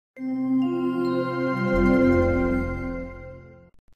shutdown.mp3